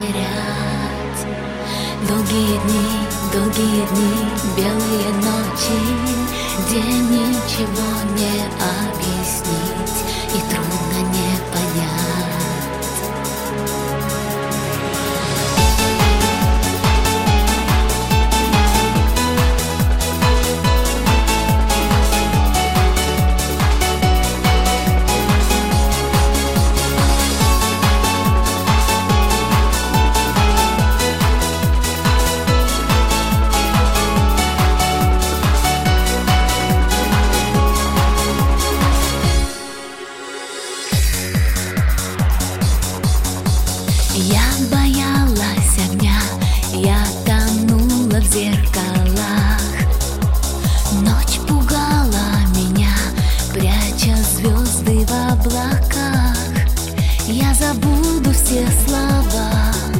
Pop
44.1 kHz, Stereo